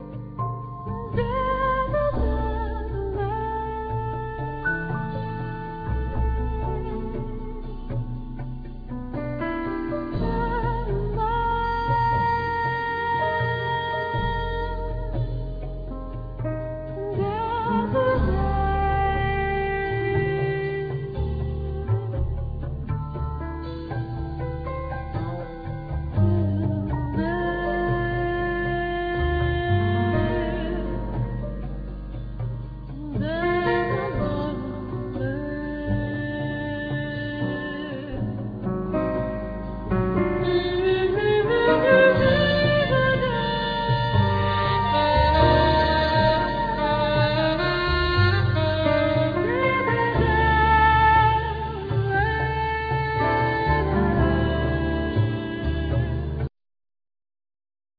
Trumpet,Flugelhorn
Soprano+Tenor Saxophone
Vocals
Keyboards,Piano,Percussions
Bass
Drums,Percussions
Guitar
El.guitar